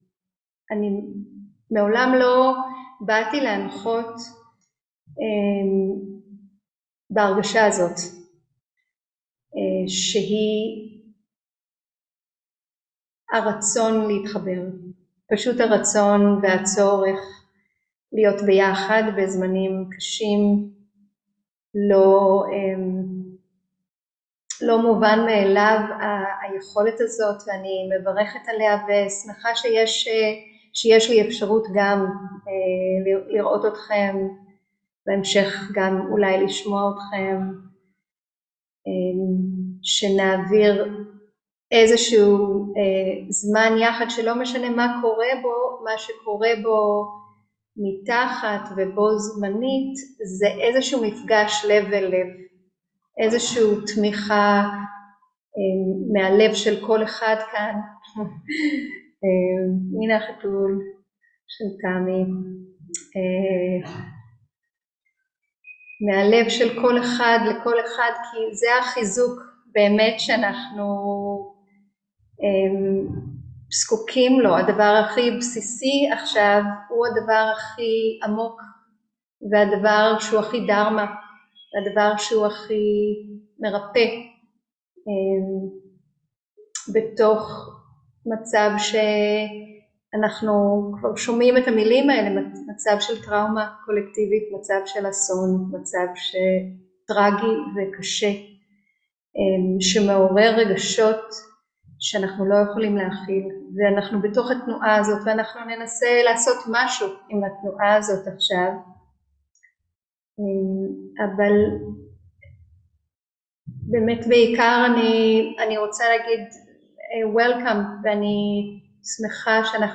13.10.2023 - מרחב בטוח - מפגש מלב אל לב - מדיטציה ותרגילי גוף, נשימה ותודעה